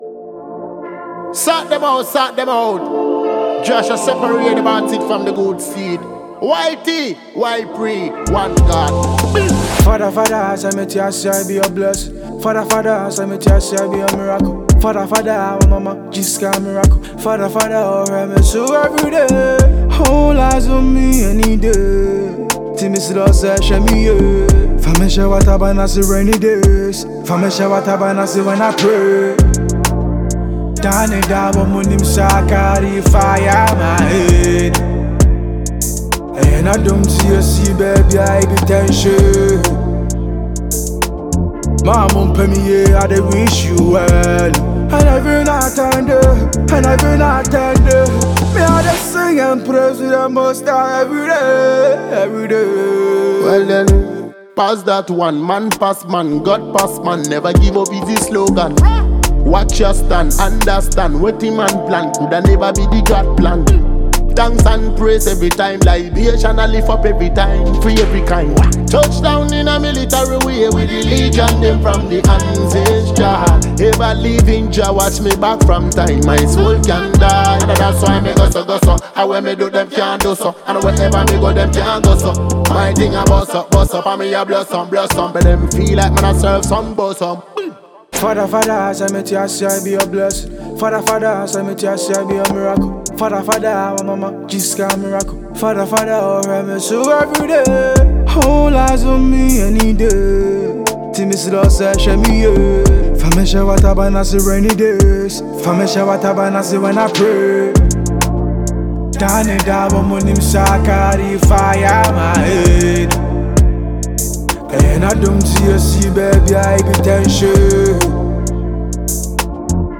Ghanaian Fast-rising rapper